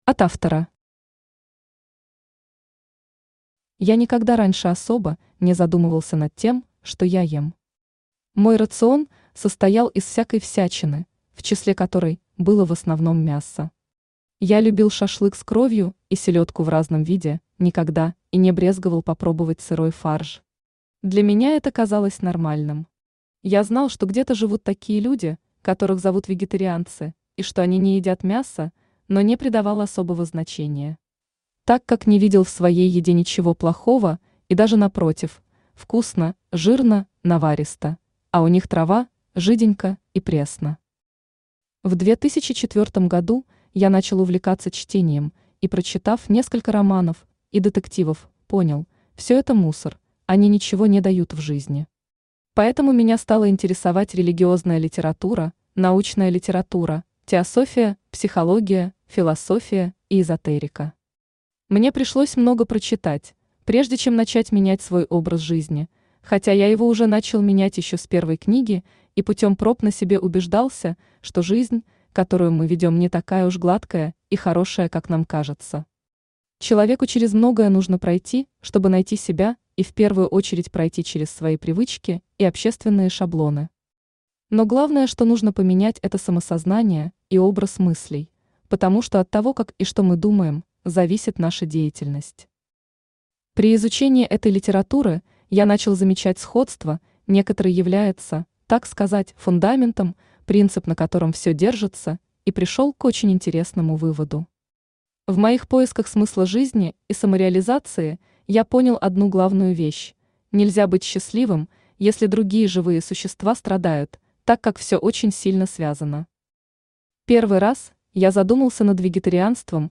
Читает: Авточтец ЛитРес
Аудиокнига «Вегетарианство. Религии, наука и бизнес».